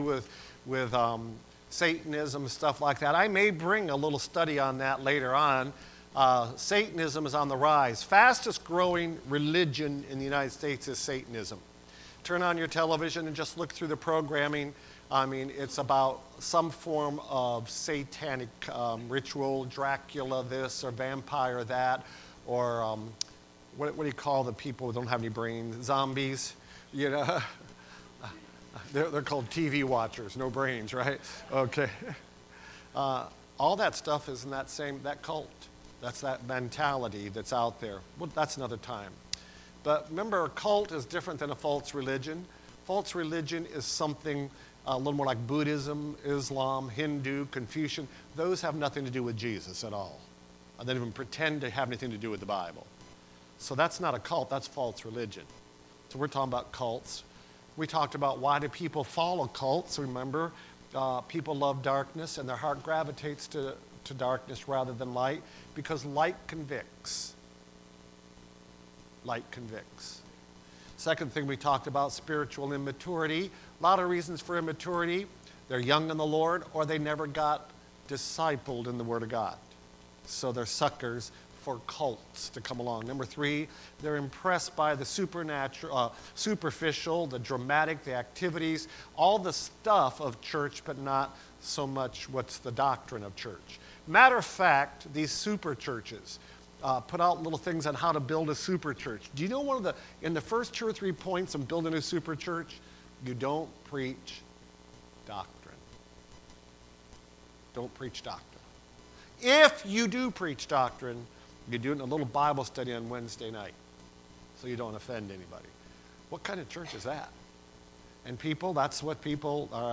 Service Type: Wednesday Prayer Service Preacher